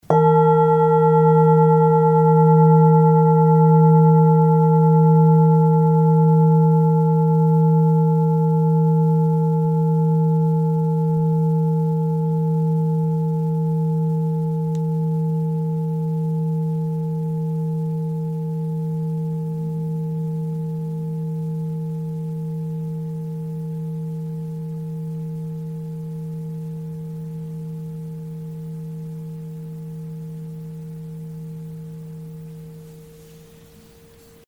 Struck or rubbed, the bowls produce a long-lasting, overtone-rich, and fine sound.
Sound sample Arhat singing bowl 900g:
Arhat-Klangschale-900g-Hoerprobe.mp3